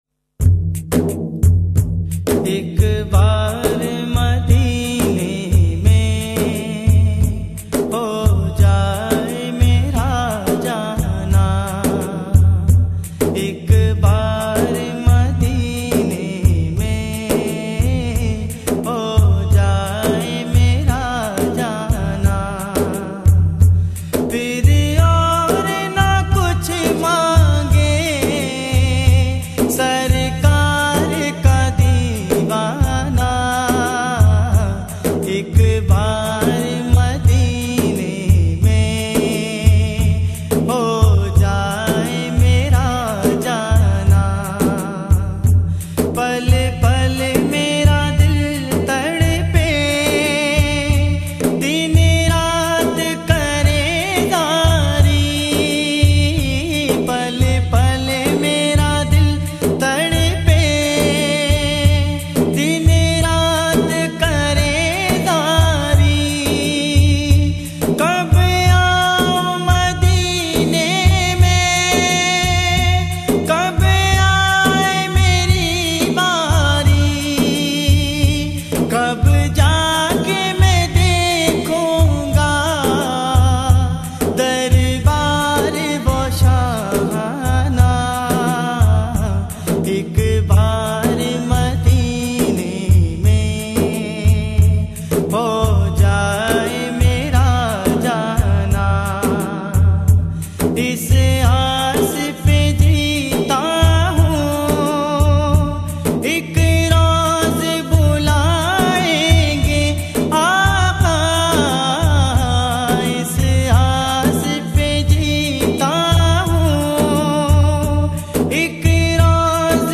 Best Islamic Naats